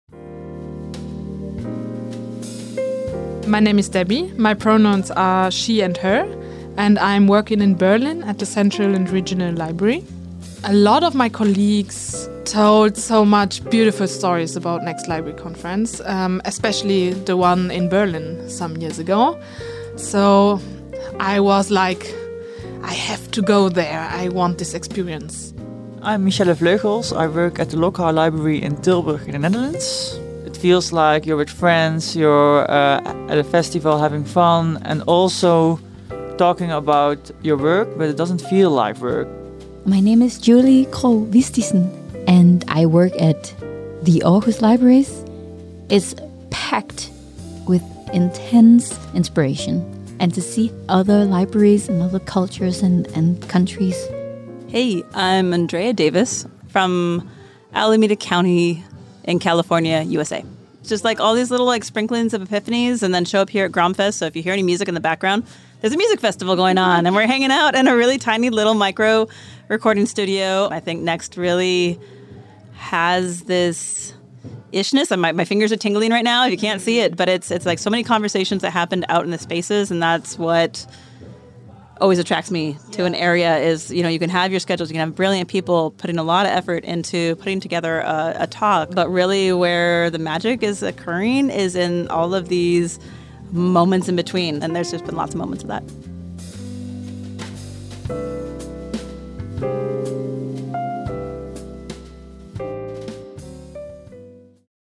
Many participants were interviewed about being at Next Library Festival in the Tiny Podcast Caravan.